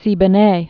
(sēbə-nā, -bō-)